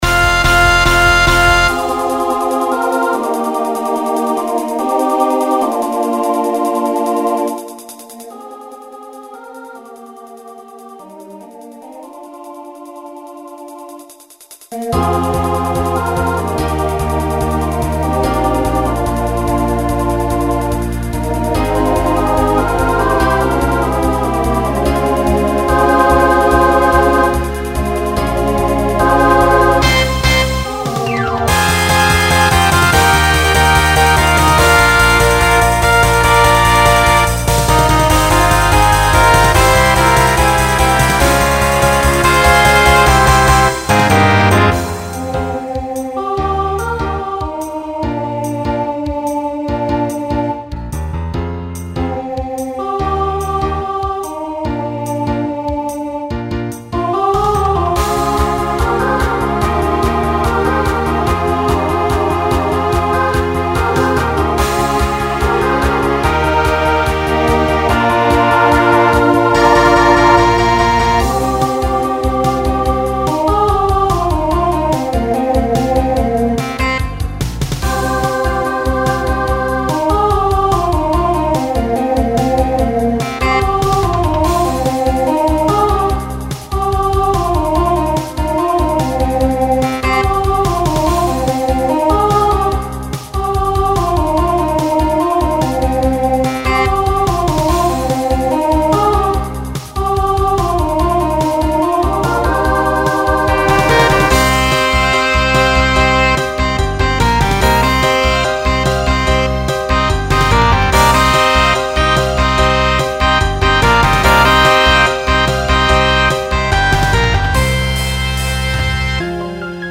Pop/Dance , Rock
Opener Voicing SSA